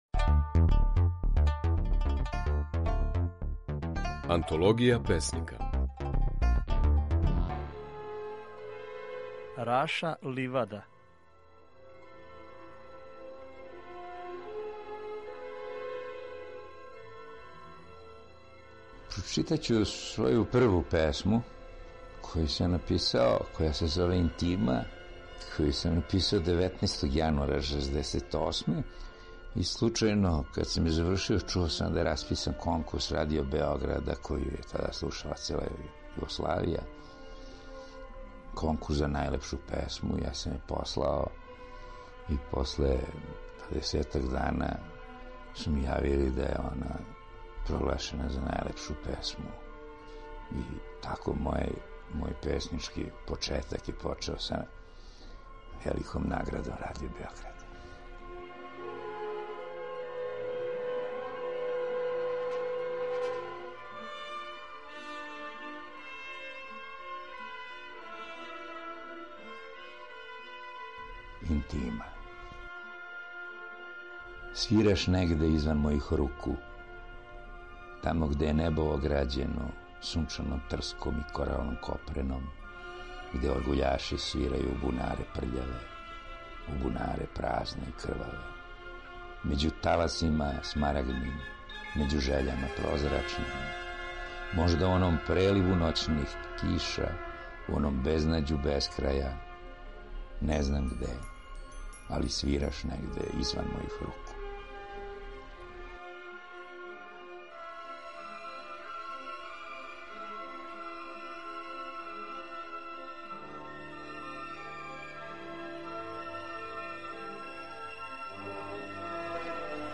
Радио-игра
Производња: Драмски програм Радио Београда, јун 1986. (реприза)